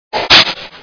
Whip
whip.wav